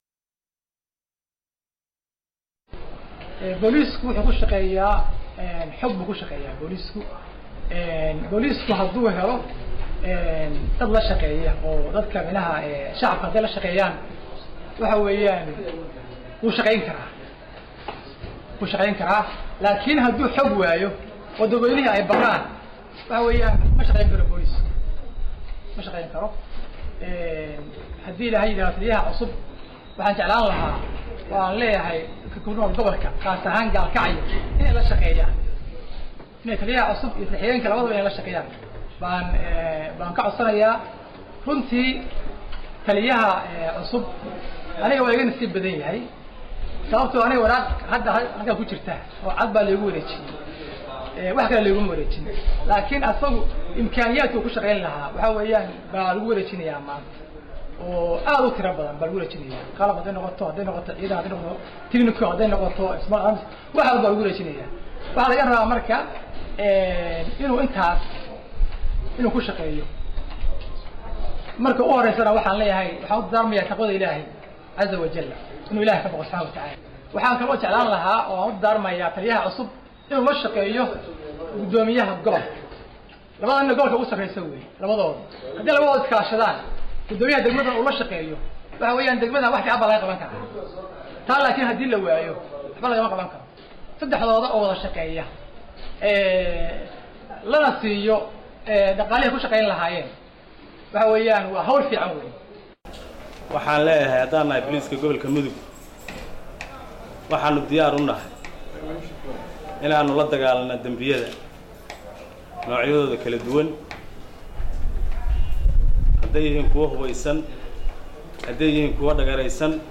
Dhegayso Taliyihii Hore ee Qaybta Booliska ee Gobolka Mudug, Taliyaha Cusub ee Qaybta Booliska Gobolka Mudug iyo Wasiirka Amniga Puntland